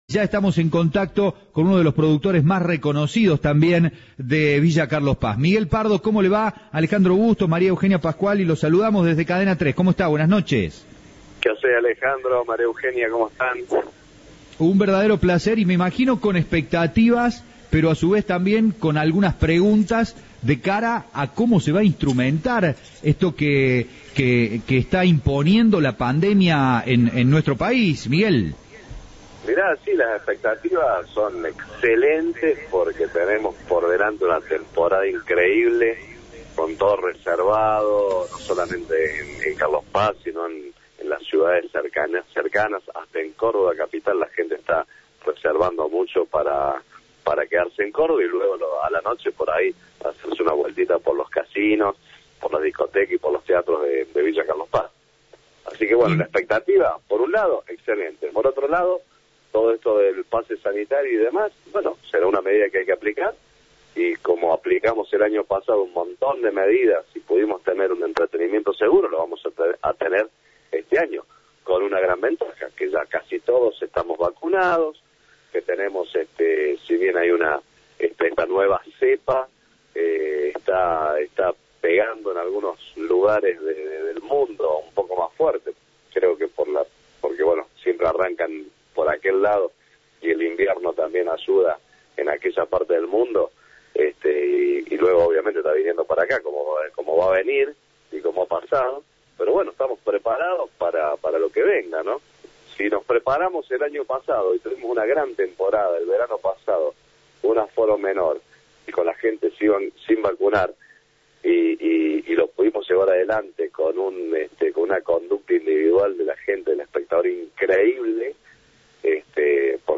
El productor teatral dialogó con Cadena 3 y adelantó algunos de los espectáculos que estarán presentes este verano en Villa Carlos Paz. El pase sanitario será una medida que hay que aplicar, indicó.